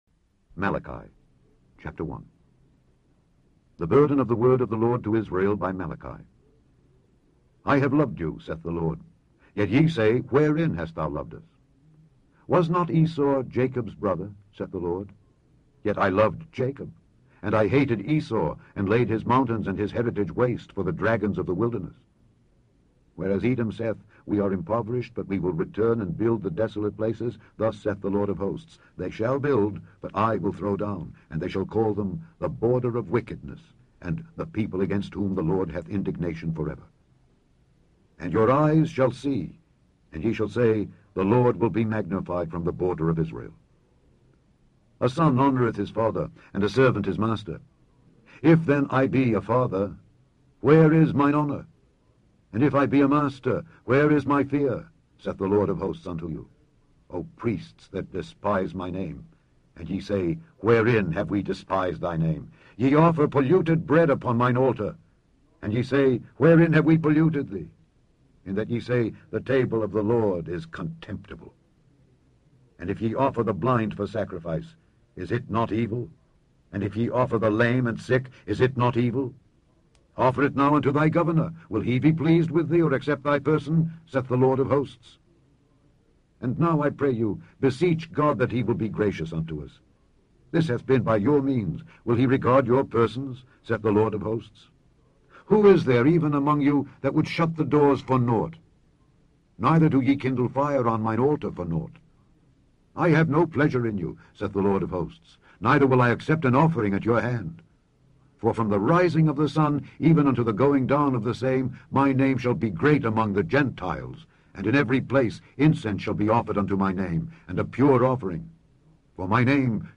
Scourby Audio Bible